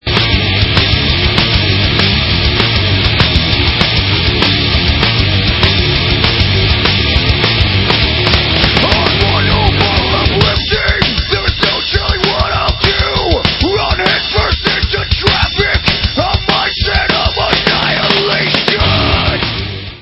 sledovat novinky v oddělení Heavy Metal
Heavy Metal